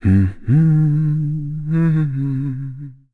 Zafir-Vox_Hum.wav